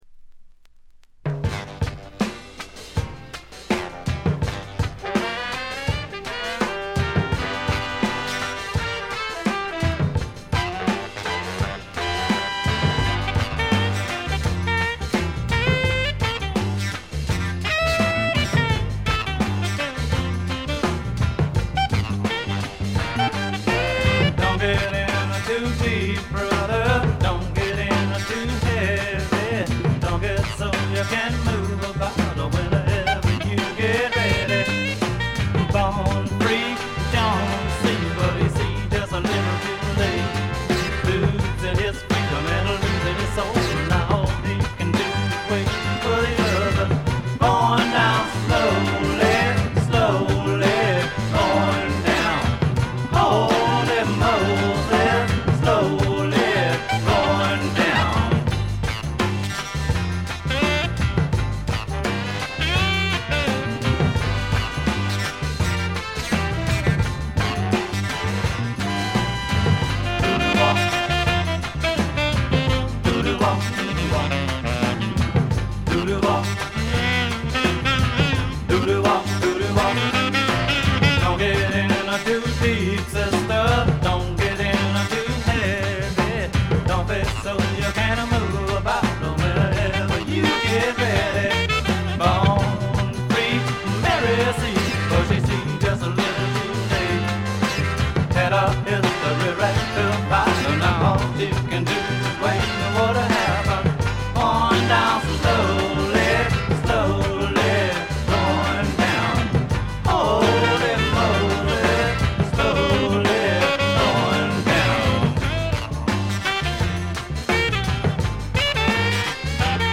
鑑賞を妨げるようなノイズはありません。
セカンドライン・ビート、ニューソウル的なメロウネス、何よりも腰に来るアルバムです。
試聴曲は現品からの取り込み音源です。